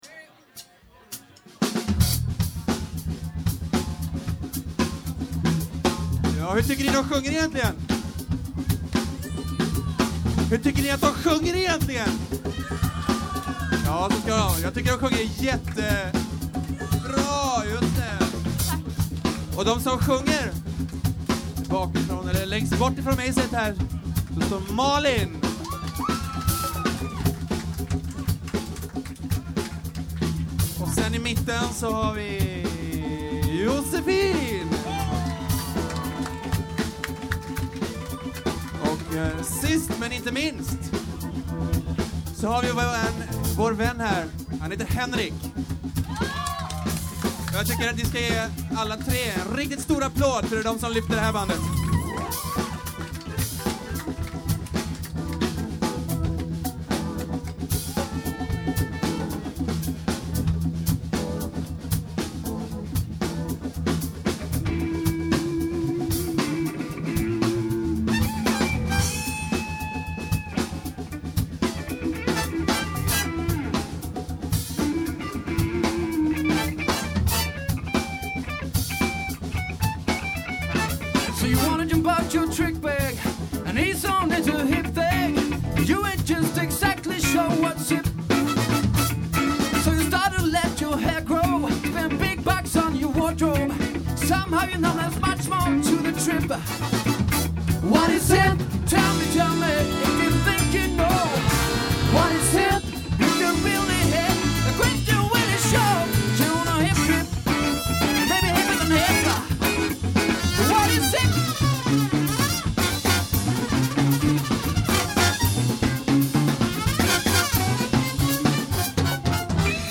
Plats: Flamman
Tillställning: Flammans vårfest 2000
Trummor
Percussion
Det rockar ganska bra tycker jag, men Flammans vårfest är ju också ett sjuhelvets partaj!